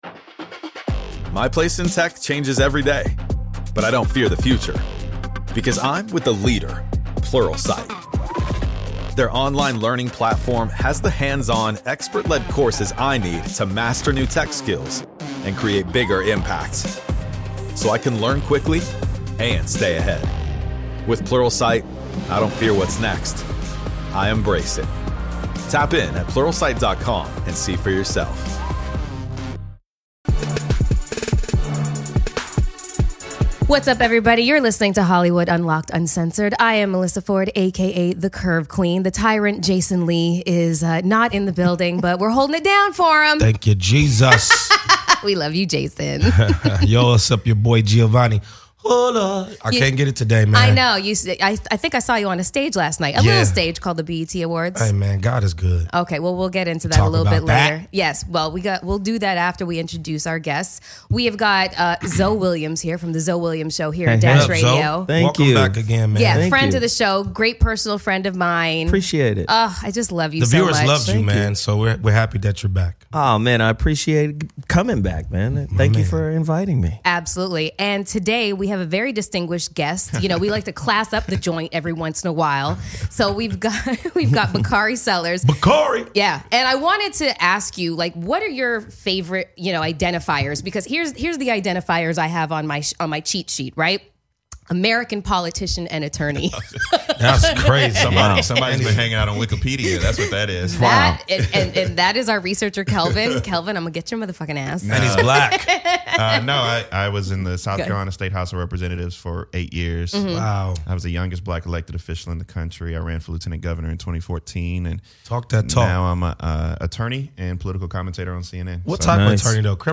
This week we are joined by Bakari Sellers.